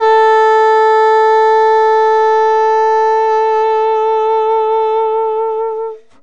中音萨克斯 (自由爵士乐) " 中音萨克斯 eb4
描述：萨克斯风系列样本的第一份。
我称它为"自由爵士"，因为有些音符与其他的音符相比是失调和前卫的。这个系列包括多种衔接方式，以获得真实的表现。
标签： 中音萨克斯 爵士 采样仪器 萨克斯 萨克斯 VST 木管乐器
声道立体声